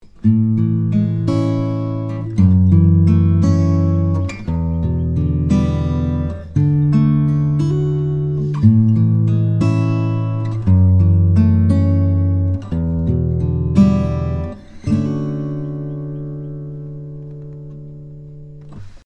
The main point is to hear what these 7th chords sound like together in a sequence.
7th chord progression example 2
Progression 2 chords are A Major 7, F# minor 7, E minor 7, and D Major 7.